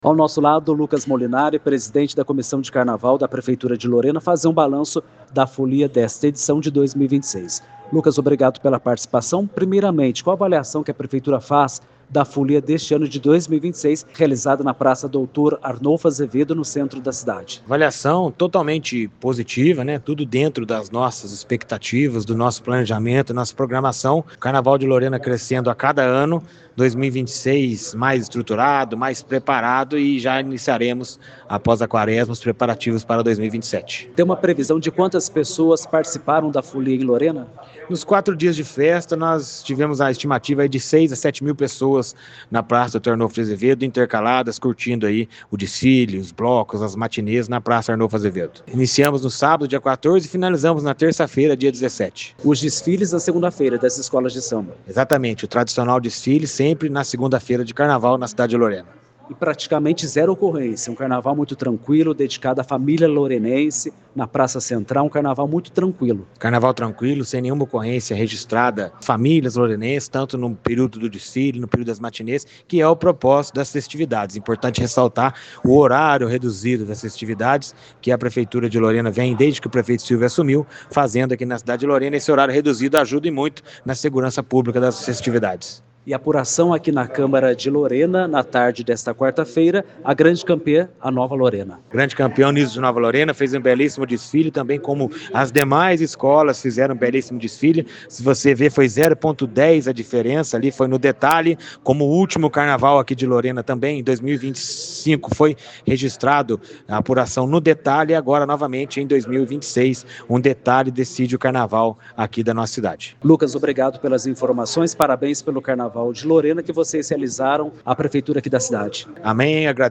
Entrevistas (áudios):